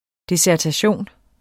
Udtale [ desæɐ̯taˈɕoˀn ]